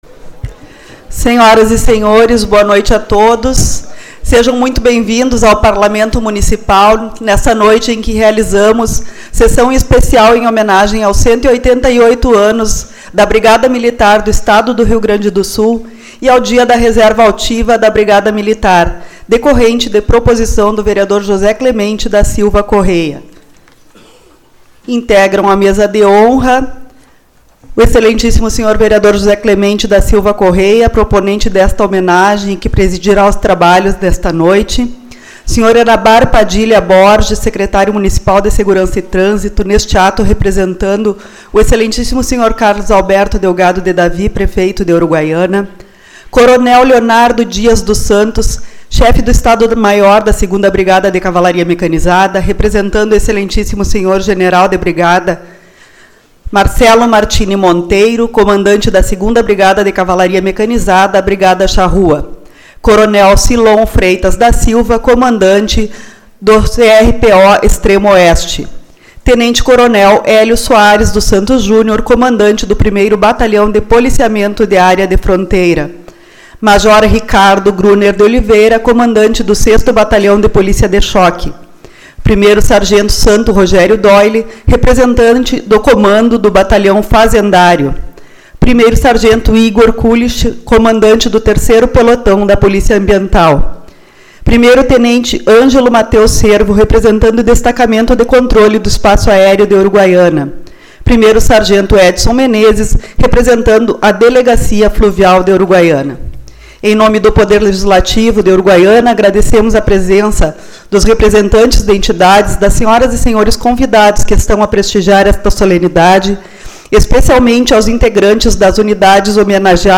12/11 - Sessão Especial-Homenagem a Brigada Militar e Reserva Altiva